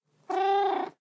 cat_purreow1.ogg